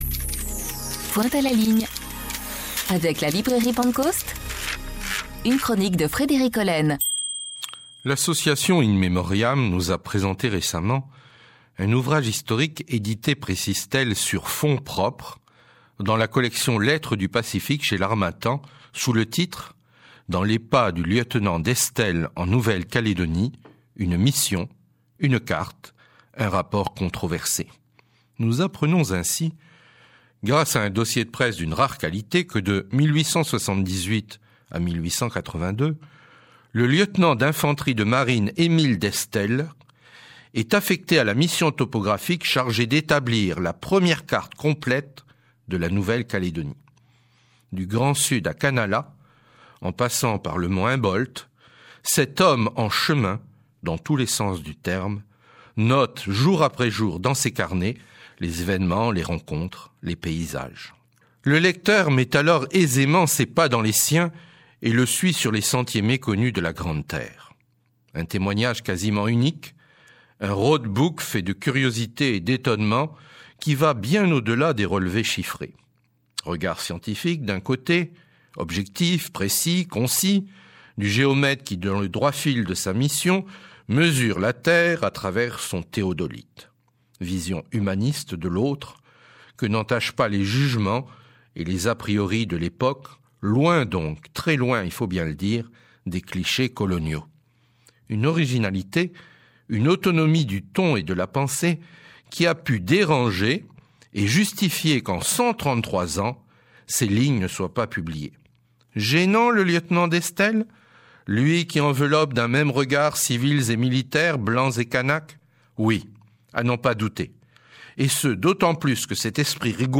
Émission Radio